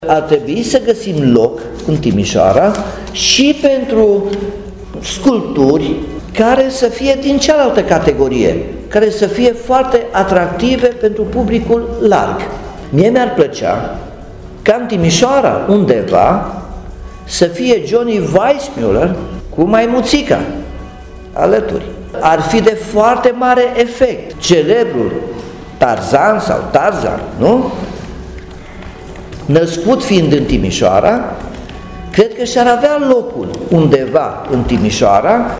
Operele au fost alese de arhitecții care au lucrat la proiectul de reabilitare, spune primarul Nicolae Robu, care a precizat că şi-ar dori ca pe viitor la Timişoara să fie şi o statuie a lui Tarzan cu maimuţa sa, pentru a se aminti că aici s-a născut Johnny Weissmuller, actorul care a interpretat acest personaj.